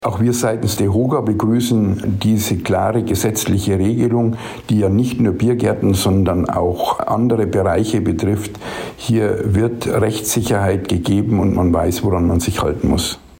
Interview: Das sagt die DEHOGA zum Cannabis-Verbot in der Gastronomie - PRIMATON